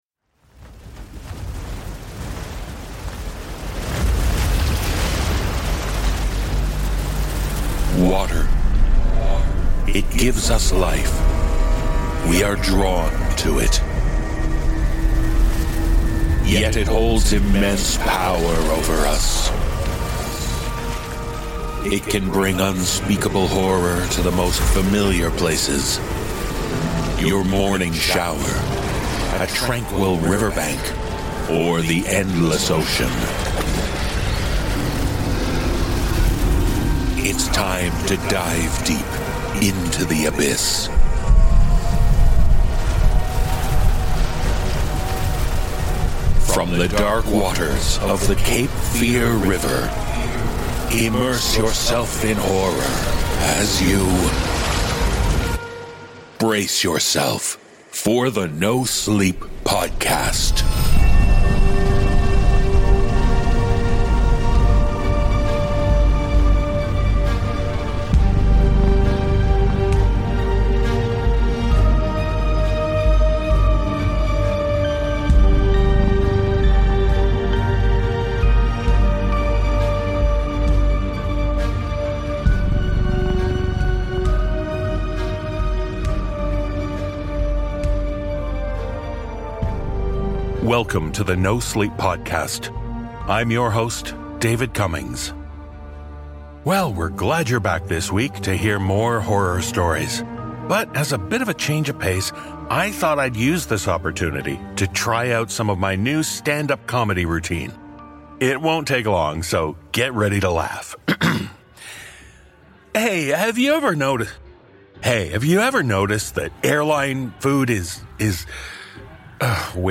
The NoSleep Podcast is Human-made for Human Minds. No generative AI is used in any aspect of work.